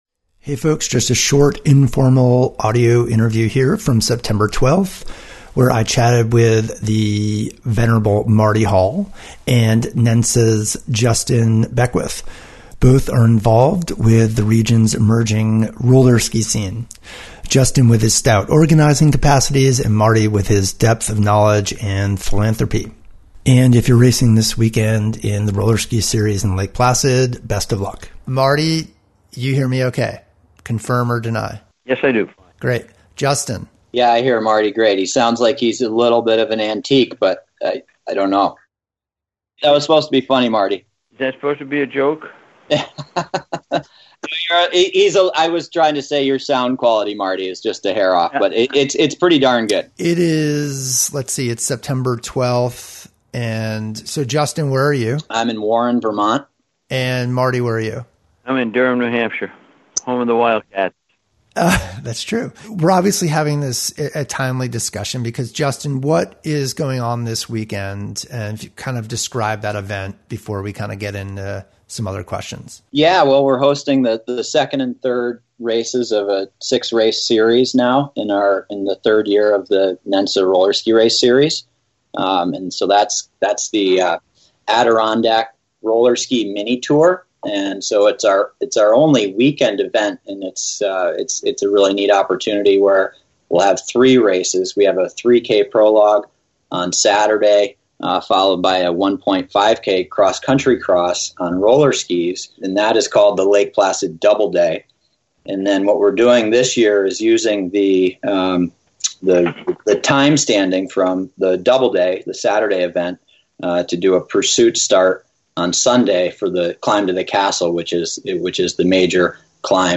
Here’s an audio short of the interview with two people making things happen.